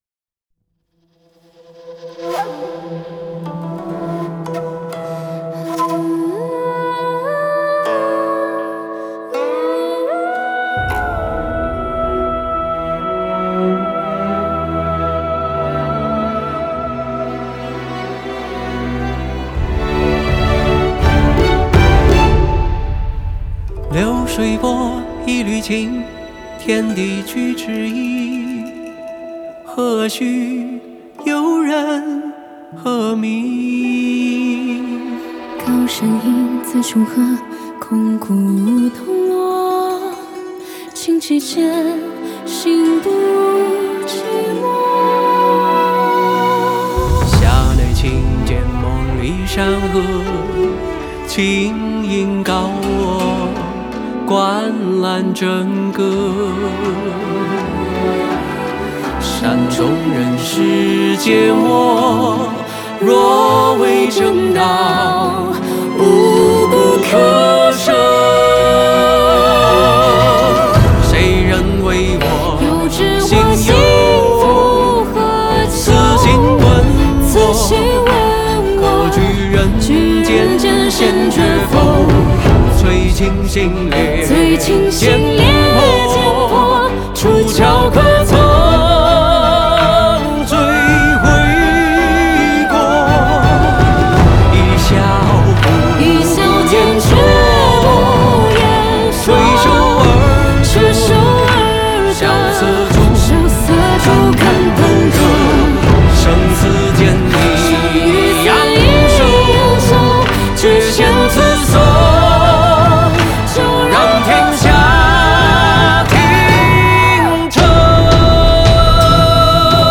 Ps：在线试听为压缩音质节选，体验无损音质请下载完整版
管弦乐团
古琴
笛/箫
吉他/贝斯